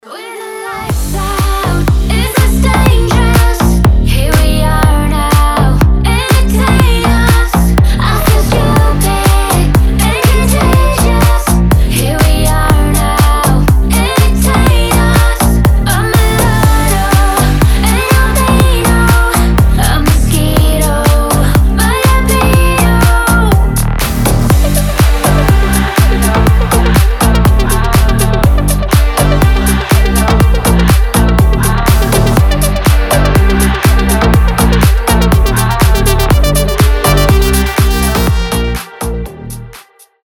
• Качество: 320, Stereo
EDM
future house
Cover
красивый женский голос
slap house